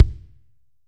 B.B KICK 9.wav